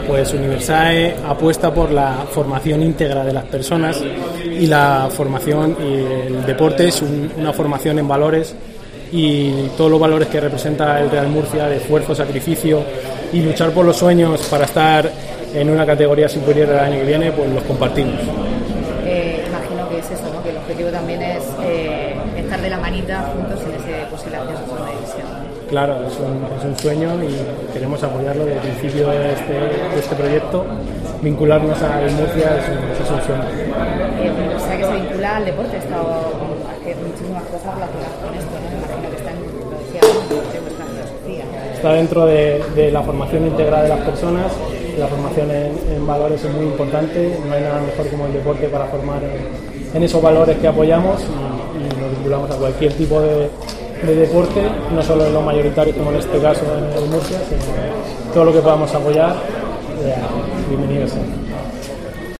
Visita a las instalaciones de Universae